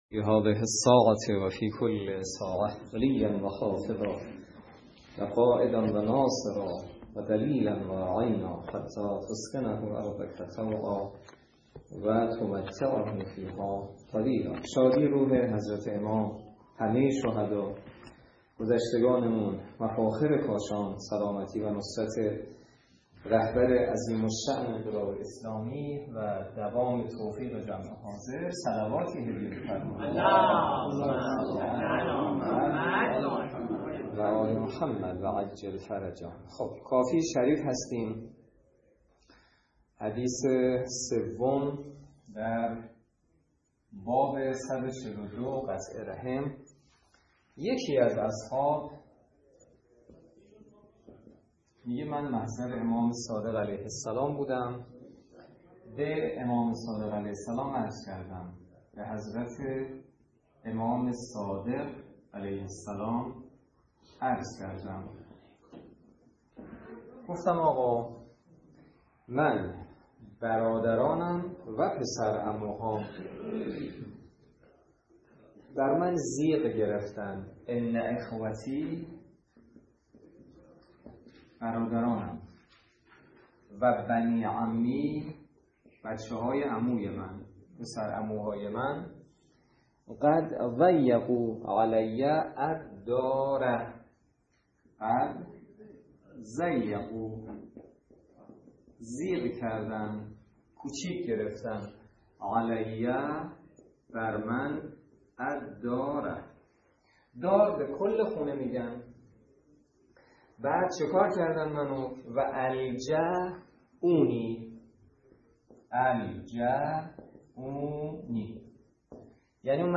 درس فقه الاجاره نماینده مقام معظم رهبری در منطقه و امام جمعه کاشان - سال سوم جلسه پنجاه و دو